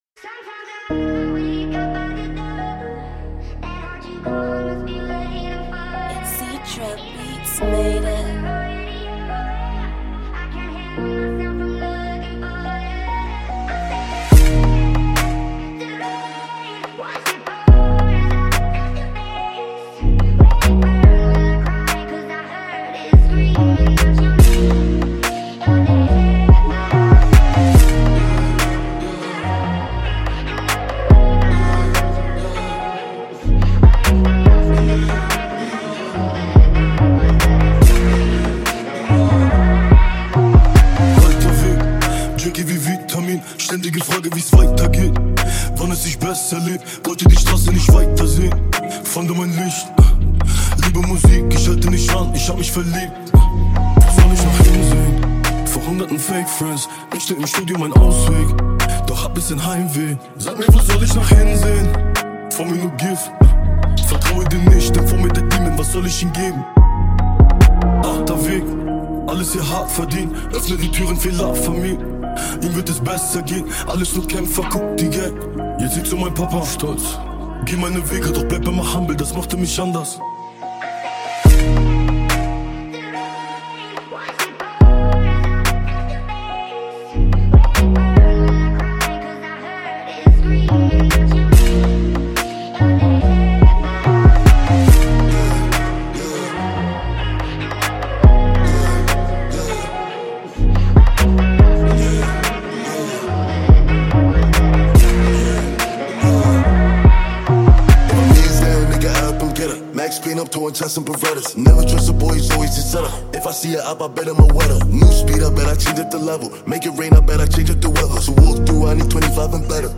tik tok mashup speed up
tik tok remix